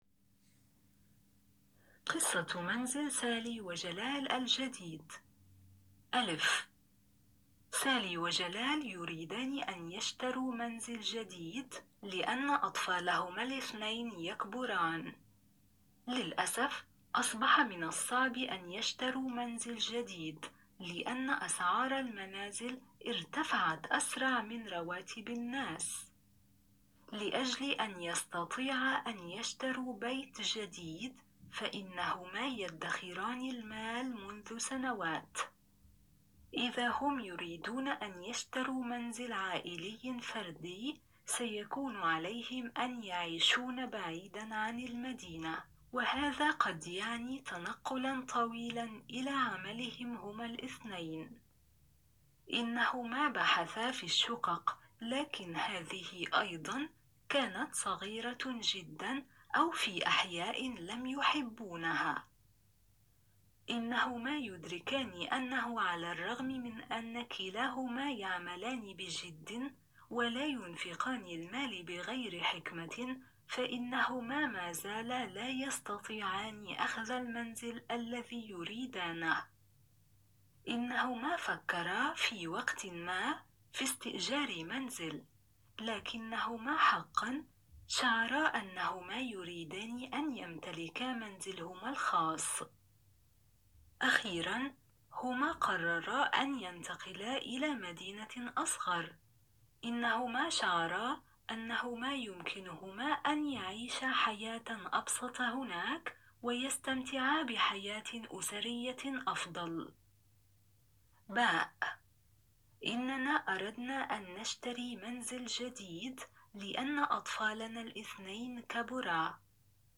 در هر بخش که حدود سه تا پنج دقیقه هست یک داستان ساده و کوتاه با دو زاویه دید متفاوت (برای مثال اول شخص و سوم شخص) و با صدای یک راوی بومی زبان روایت میشه و در پایان هم شما باید در حد توا...